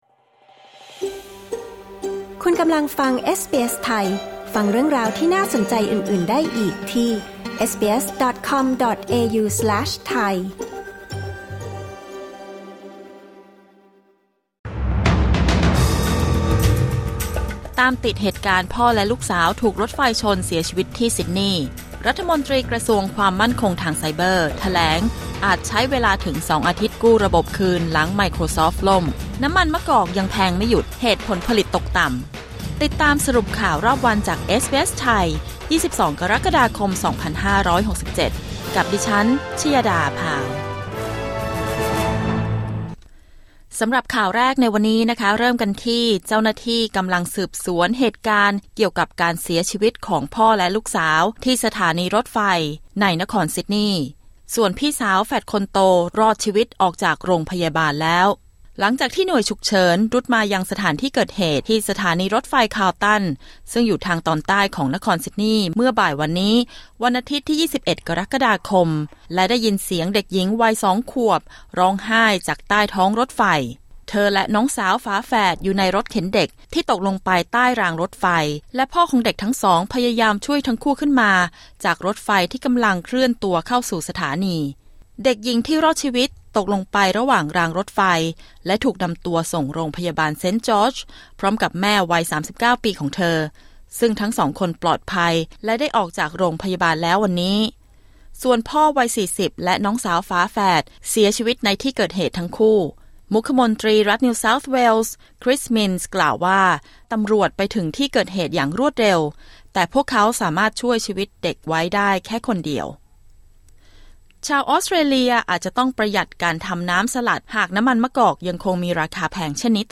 คลิก ▶ ด้านบนเพื่อฟังรายงานข่าว